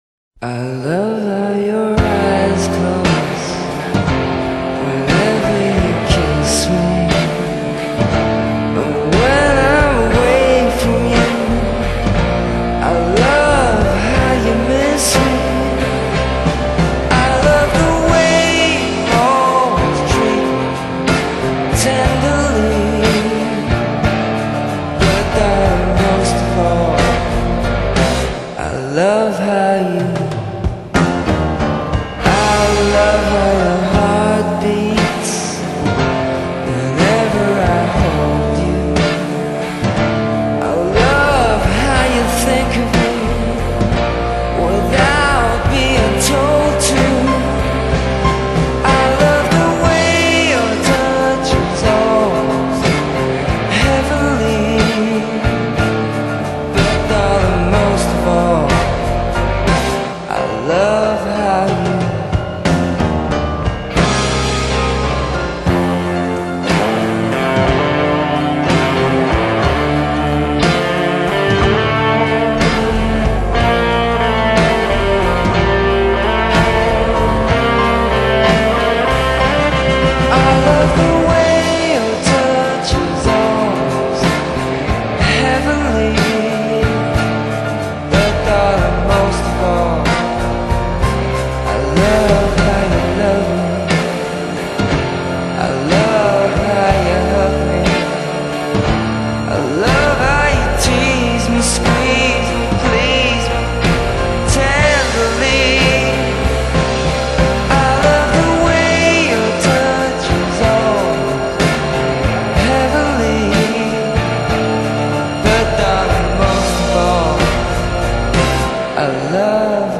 音乐不错，但音质不够好！